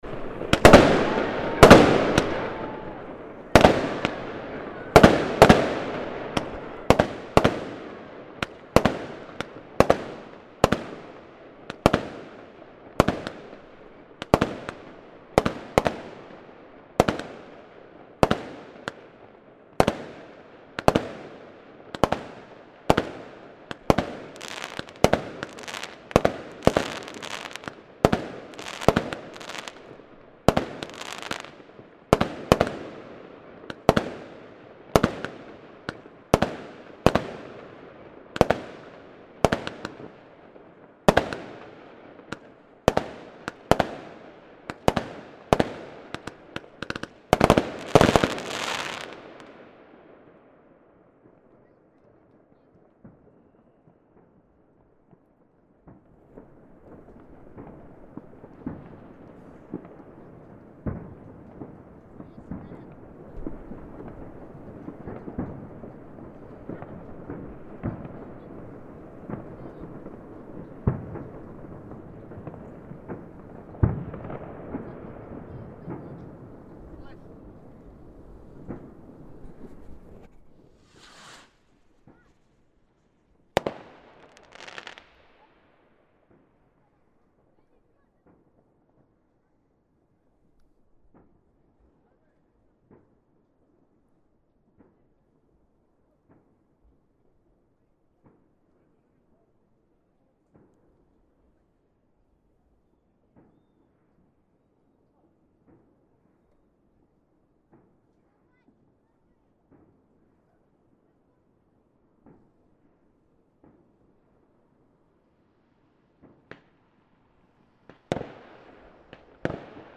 Ну и просто подборка залпов помощнее.